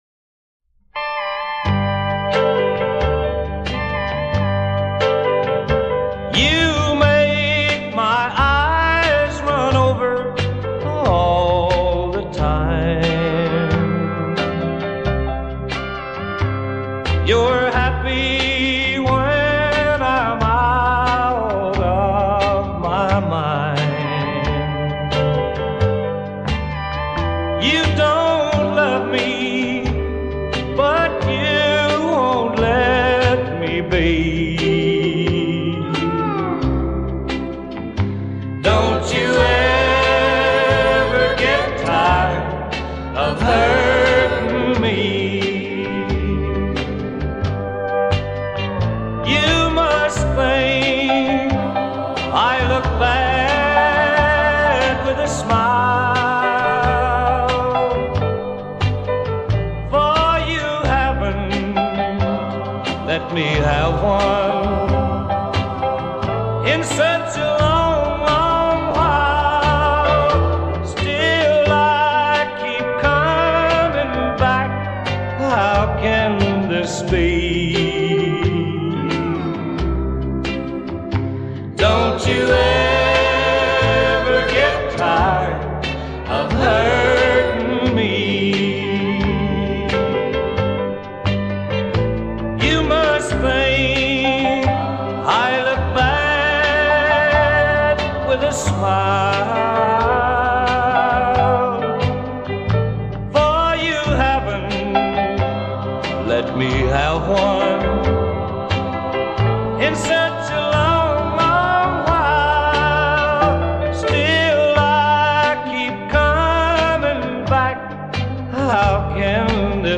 Lesser known Country star.